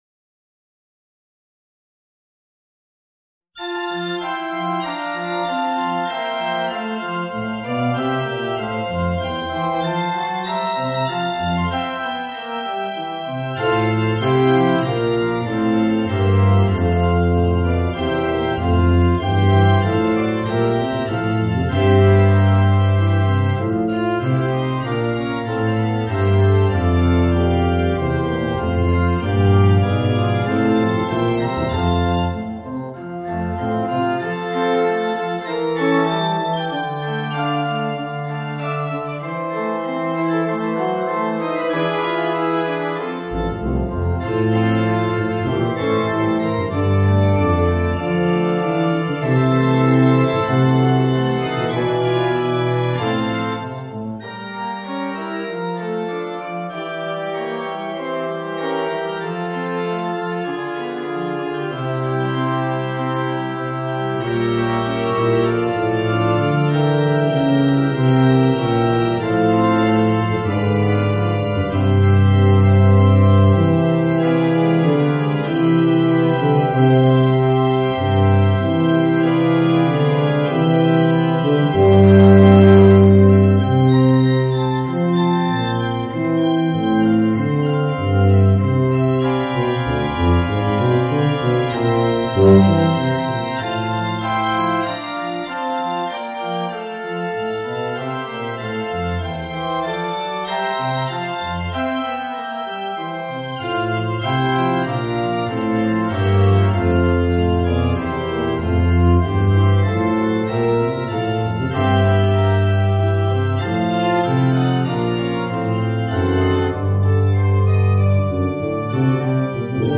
Voicing: Bb Bass and Organ